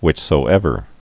(wĭchsō-ĕvər, hwĭch-)